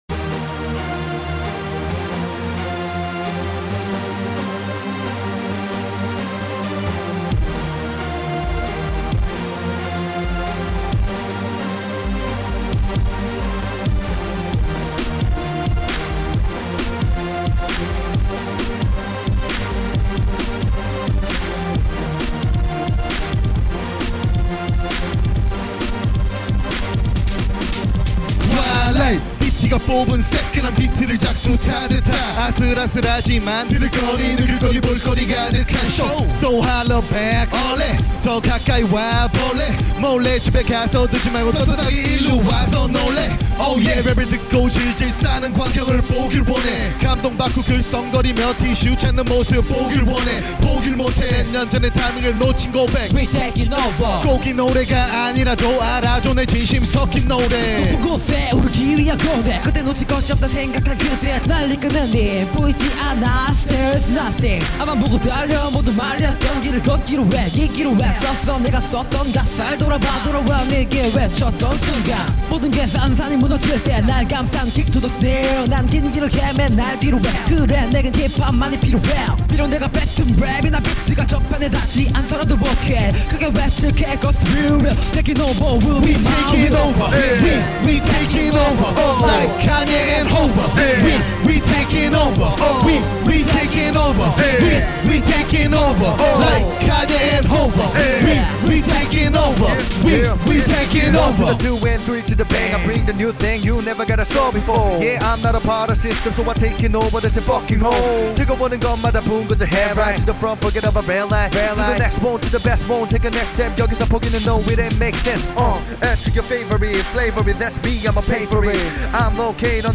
5시간만에 나온 벙개곡입니다